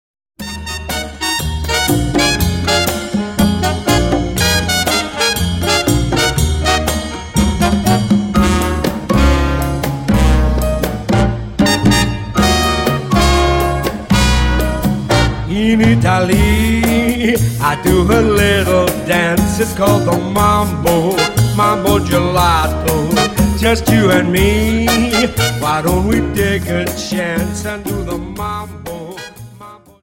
Cha Cha 31 Song